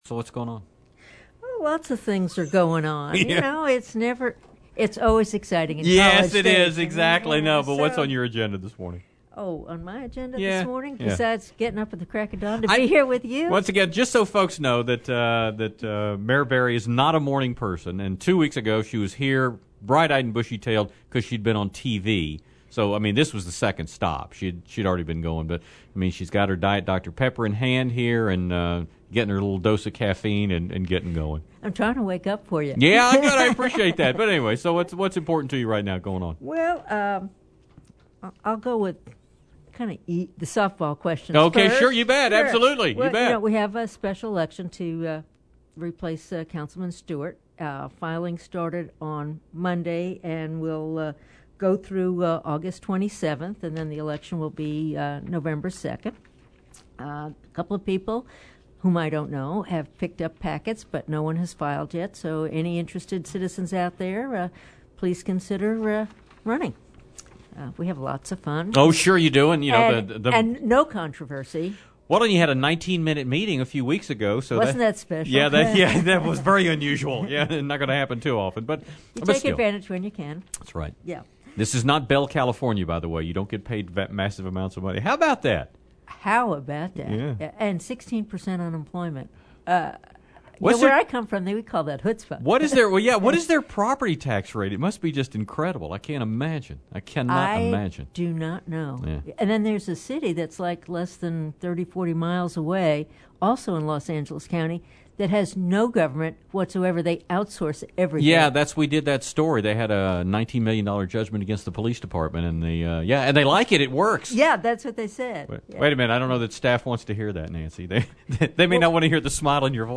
Nancy Berry Interview – July 28, 2010